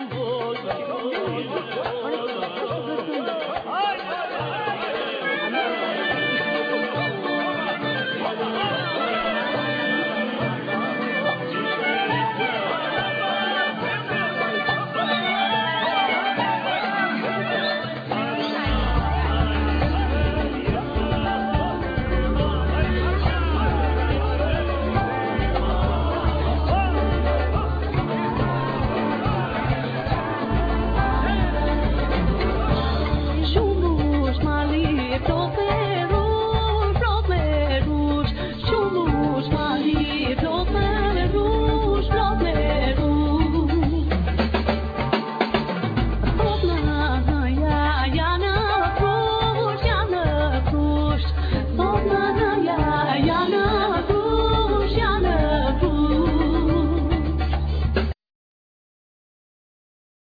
Vocals
Piano
Drums
Double bass, E-bass
Bakllama, Lute, Percussions
Accordion
Solo Clarinet